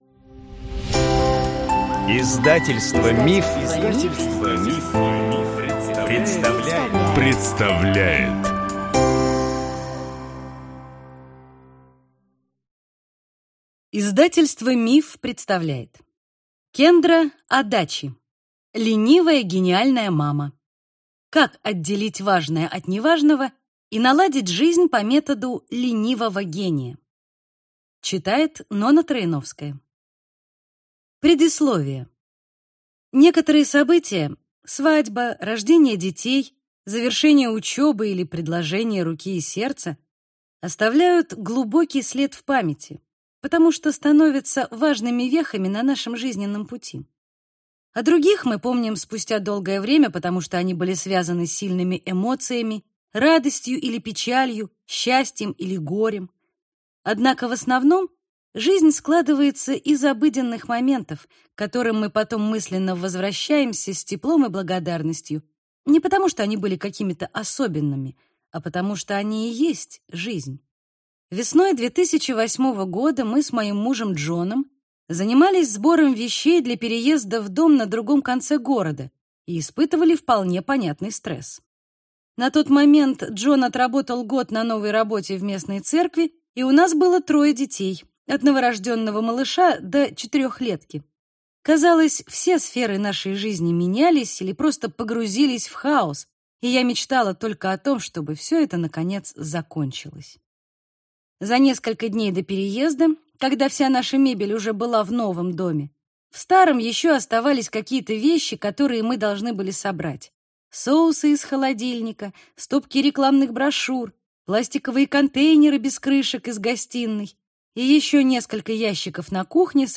Аудиокнига Ленивая гениальная мама. Как отделить важное от неважного и наладить жизнь по методу «ленивого гения» | Библиотека аудиокниг